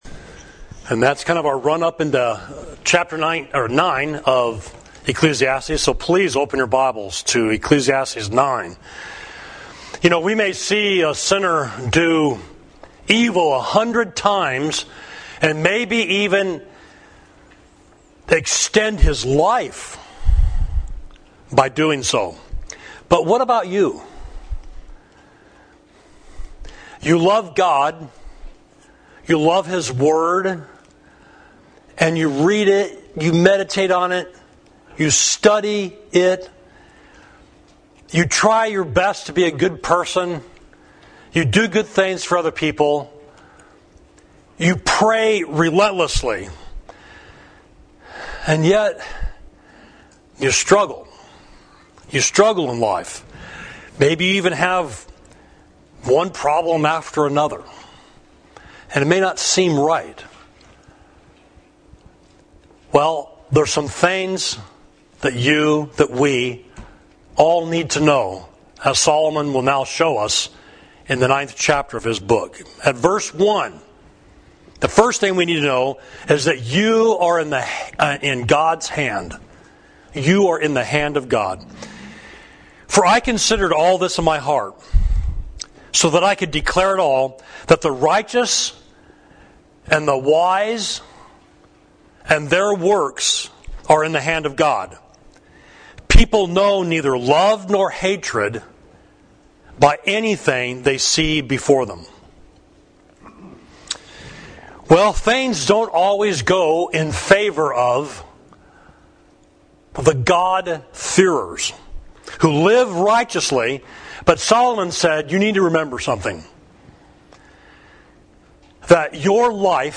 Sermon: Your Life Is in the Hand of God – Savage Street Church of Christ